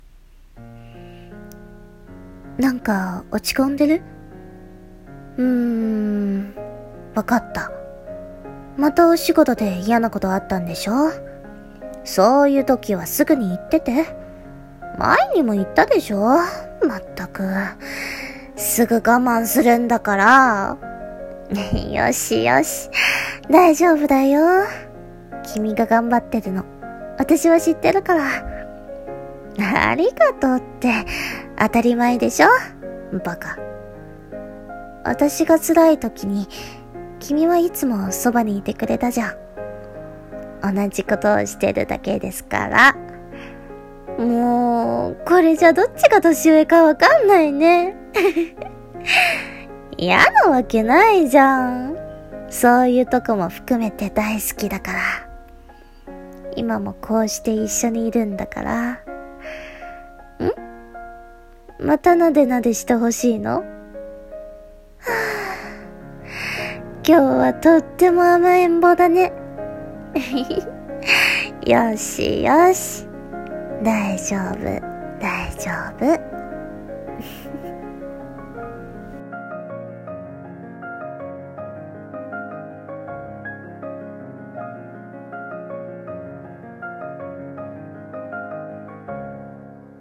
声劇[大丈夫]【１人声劇】